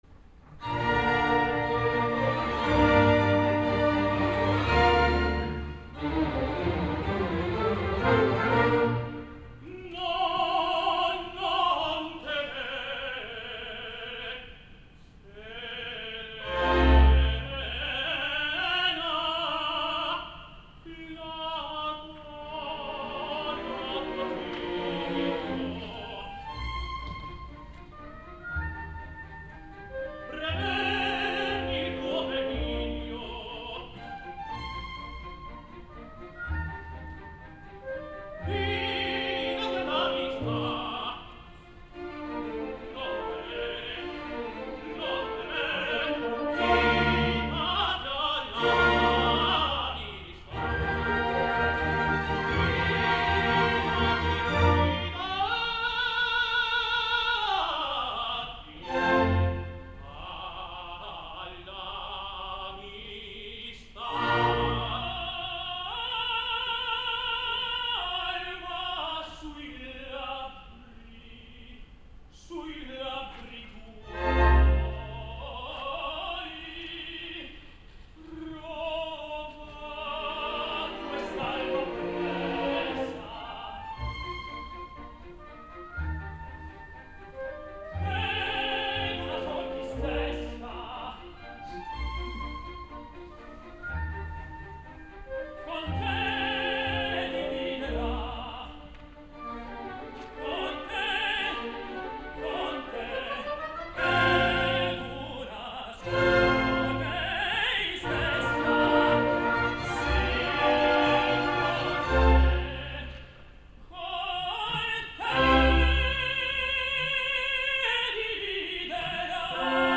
FLOREZ i ZAPATA en el OTELLO de PESARO.
Ara us vull deixar un testimoni sonor d’aquelles funcions amb el duet entre Iago (José Manuel Zapata) i Rodrigo (Juan Diego Florez) del primer acte, “No, non temer”.
Espero que ho gaudiu, és una autèntica meravella, musical i vocal.